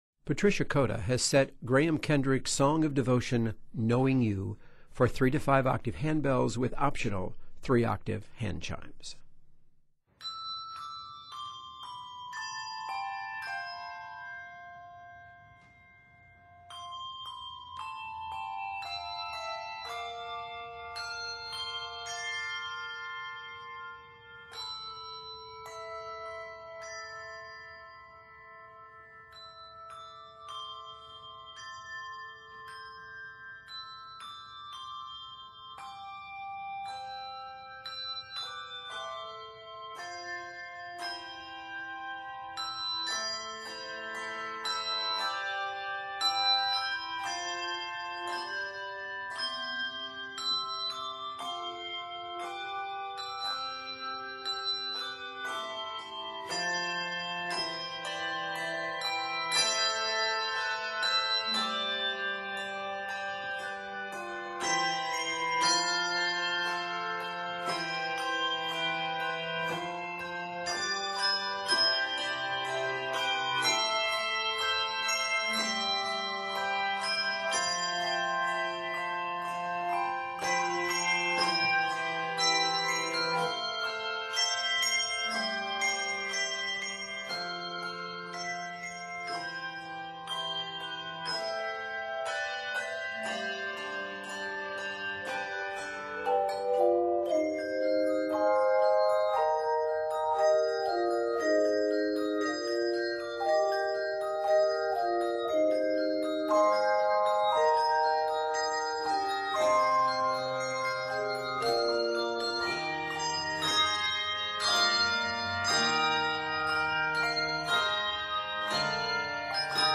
After opening in a free-flowing, contemplative style